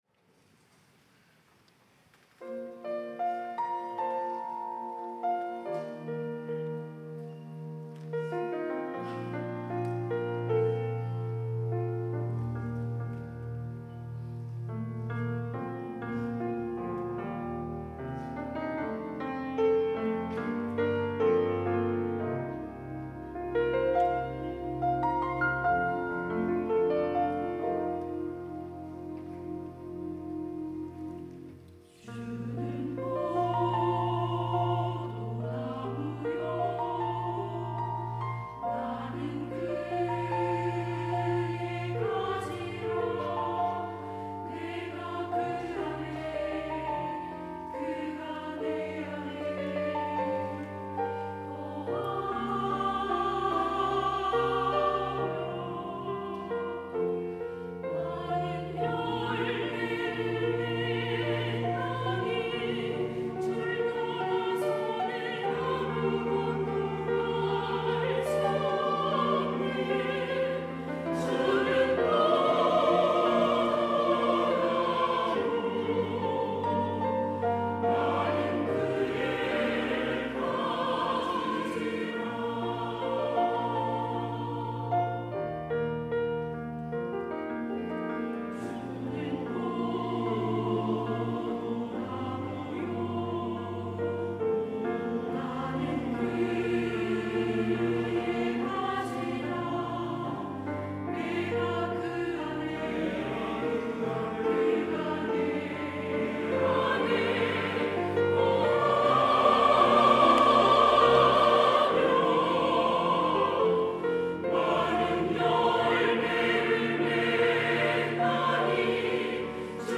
시온(주일1부) - 주는 포도나무
찬양대 시온